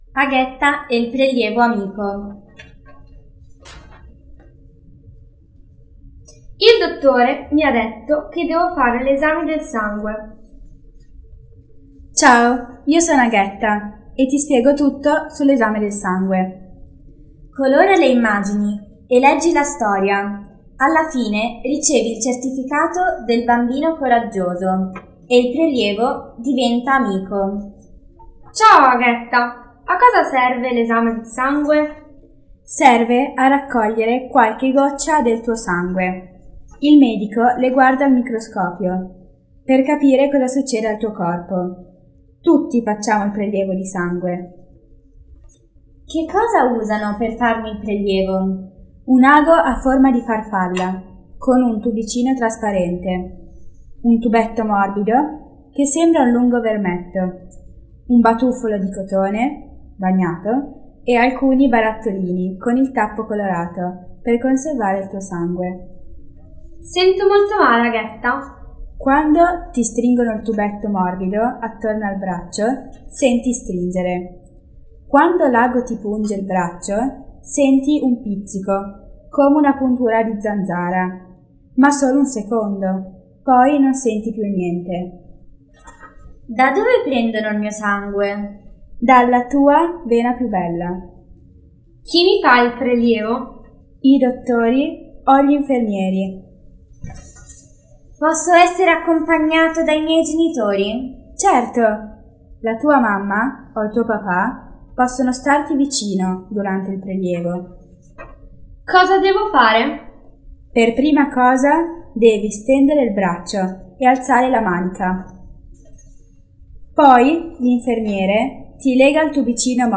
Audiolettura - Aghetta e il prelievo amico.wav